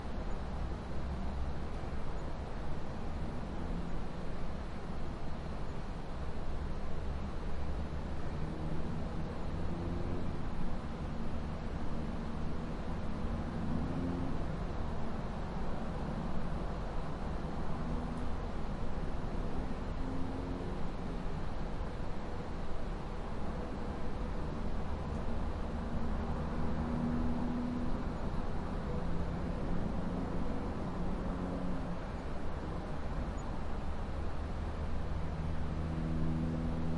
营地" 房间色调车库充满了垃圾，远处的高速公路交通繁忙，国家1
描述：充满垃圾的房间音调车库与重的遥远的高速公路交通country1.flac
Tag: 公路 交通 远处 车库 国家 房间 色调